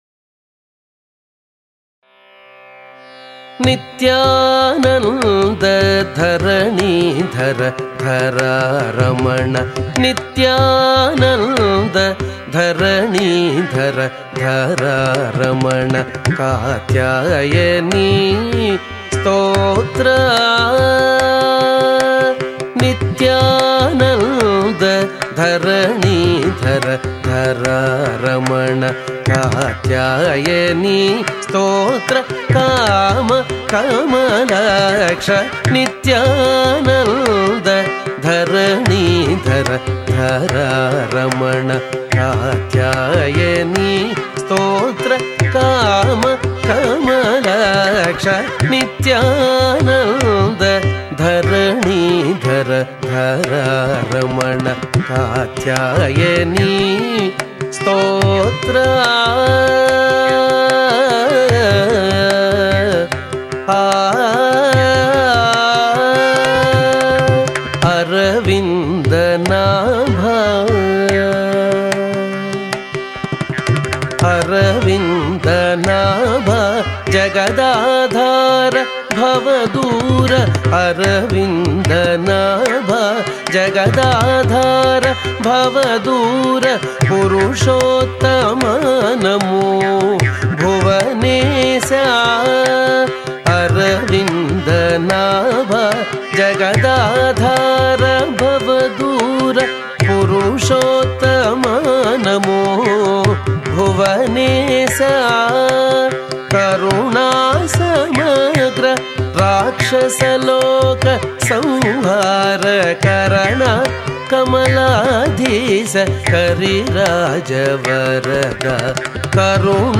సంకీర్తన